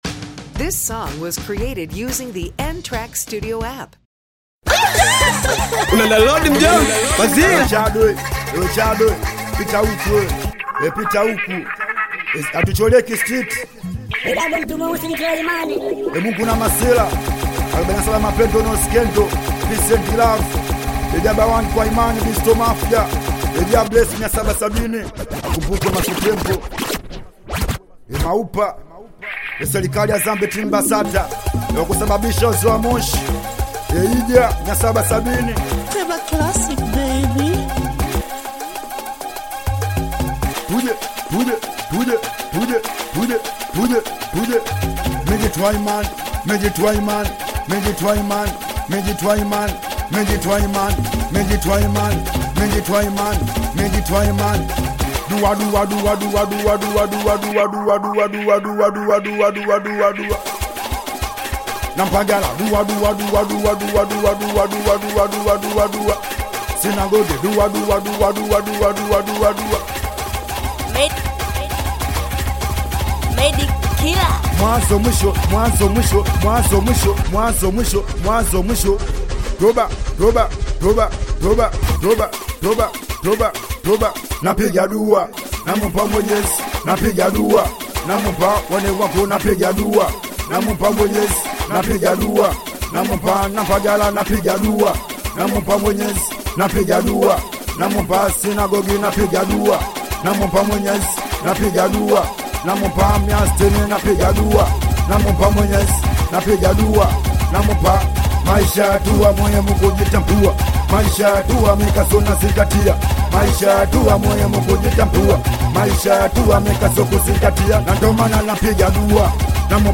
Singeli